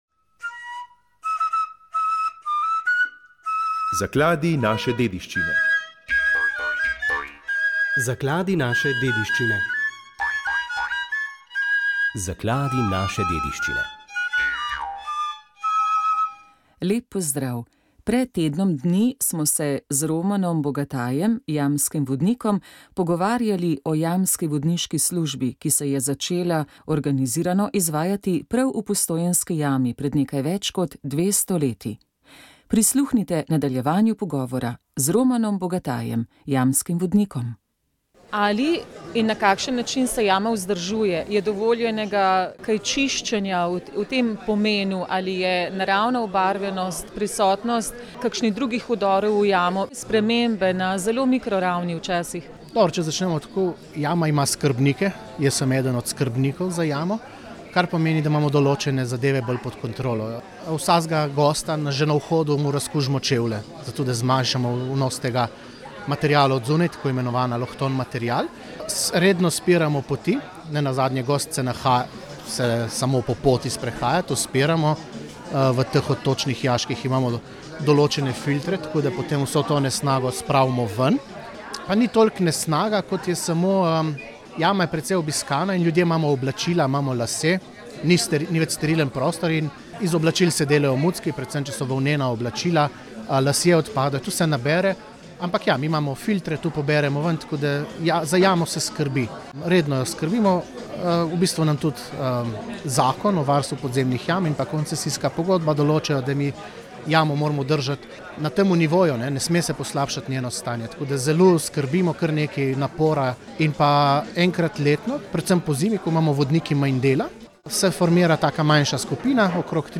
Molitev je vodil ljubljanski pomožni škof Anton Jamnik.